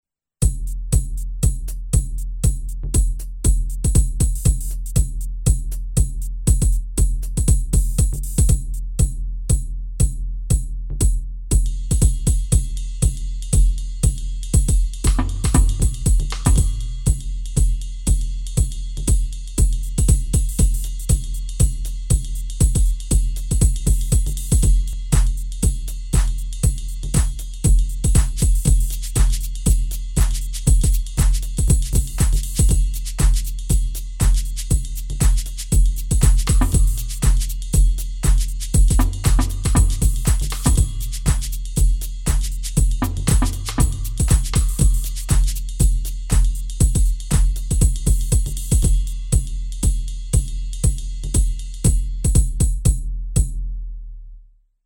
Rackmount drum brain based on PCM samples with analog triggers and basic Midi control.
Percussives sounds and drum kits are organized into 6 sounds categories: kick, cymbal, snare, tom, percussion and effects.
kit 3 demo